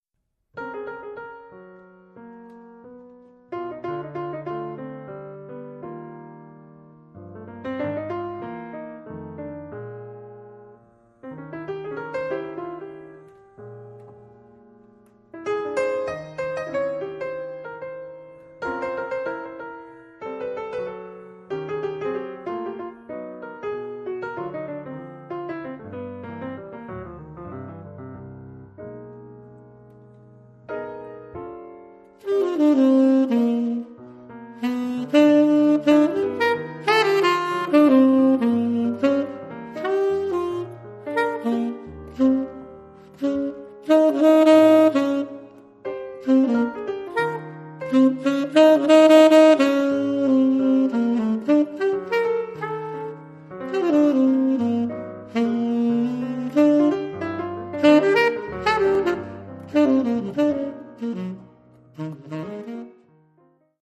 pianoforte
sax tenore